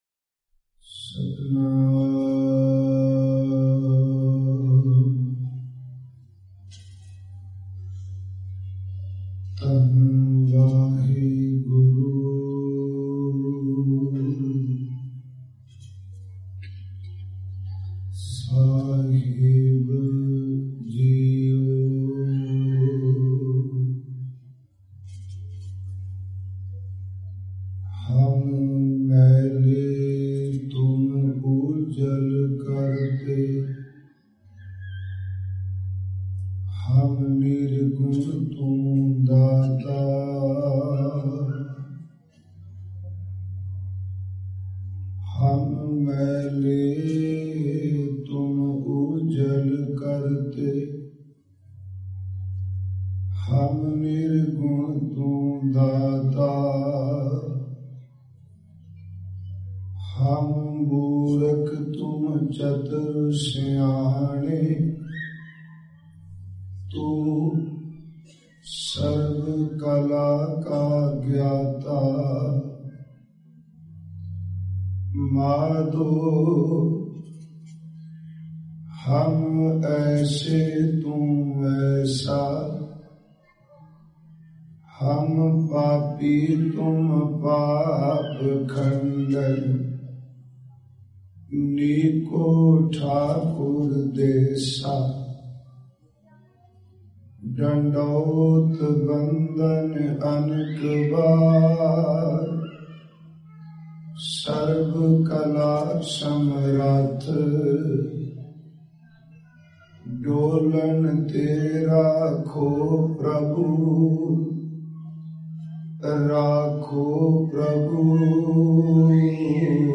Listen and Download Katha